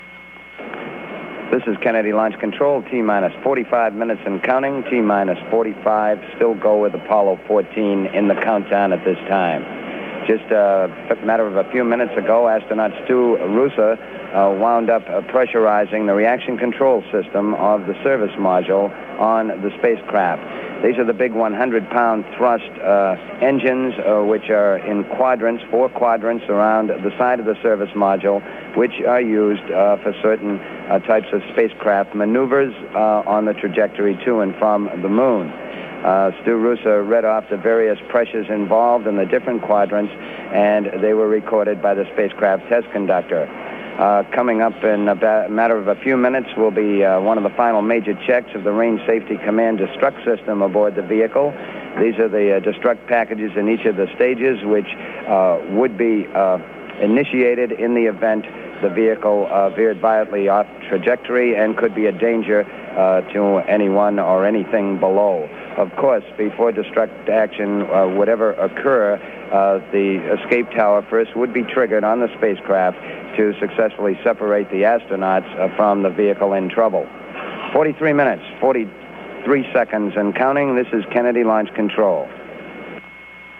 PAO loop.